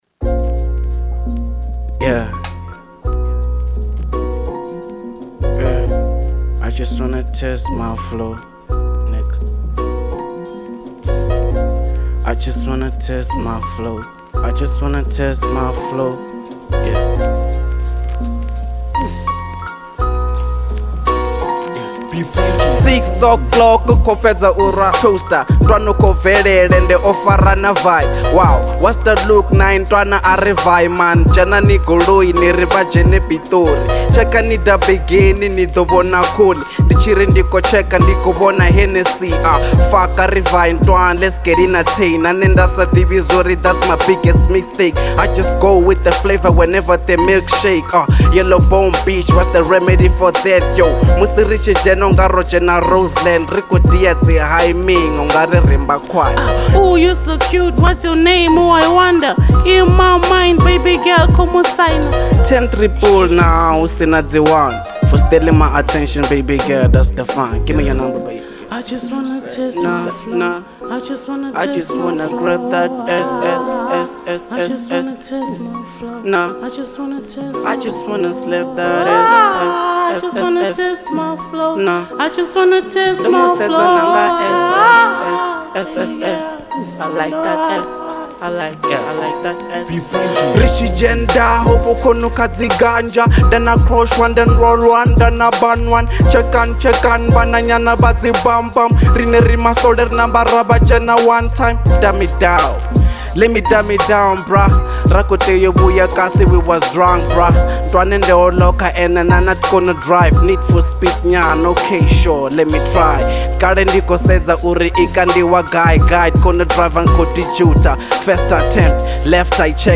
02:38 Genre : Venrap Size